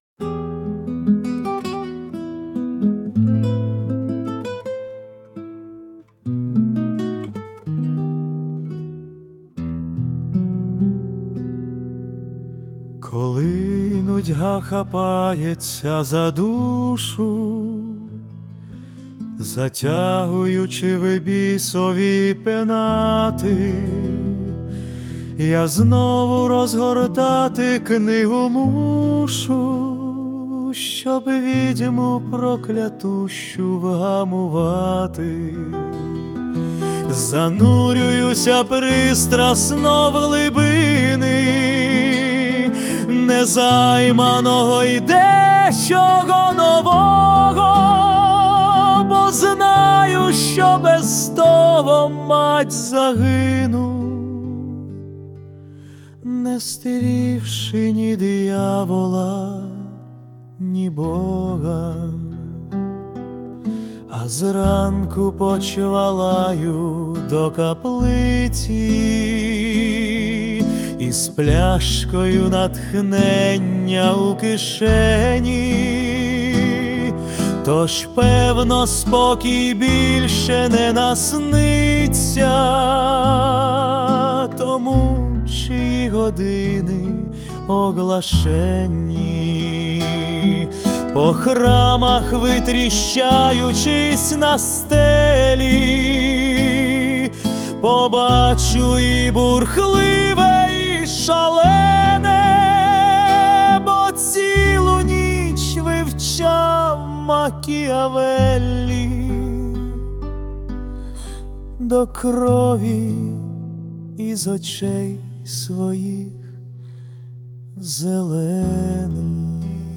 - Ну раз хотел эдакое, то получай, - прохрипел AI, изобразив что-то издалека напоминающее романс.